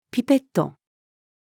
ピペット-female.mp3